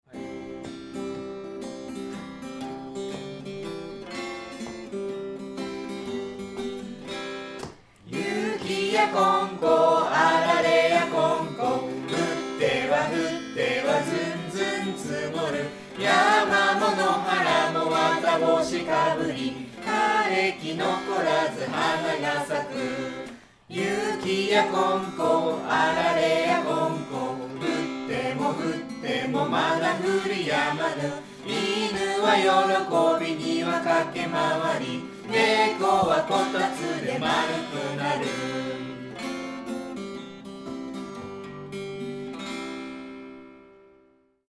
冬の歌『雪